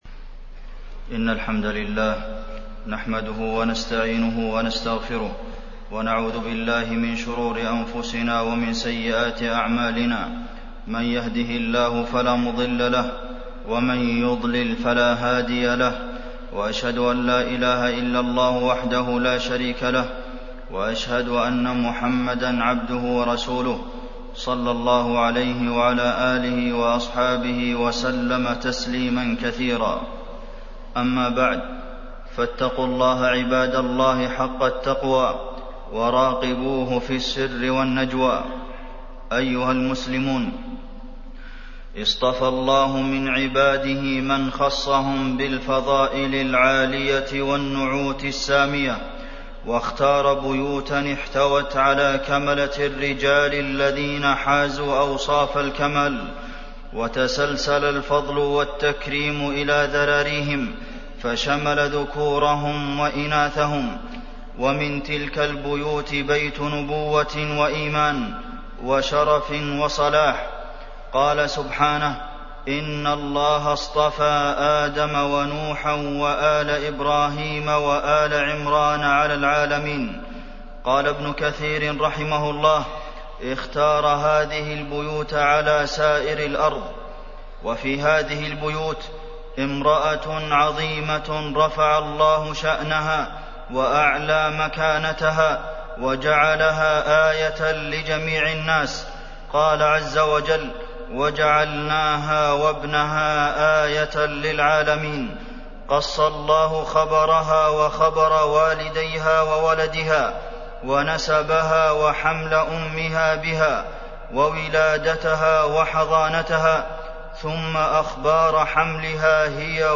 تاريخ النشر ٢٢ جمادى الأولى ١٤٢٨ هـ المكان: المسجد النبوي الشيخ: فضيلة الشيخ د. عبدالمحسن بن محمد القاسم فضيلة الشيخ د. عبدالمحسن بن محمد القاسم مريم ابنة عمران